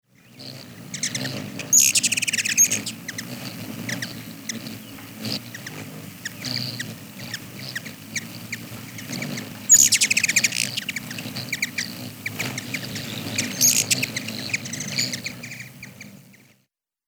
Звук беззвучного полета крошечной птицы с гигантскими крыльями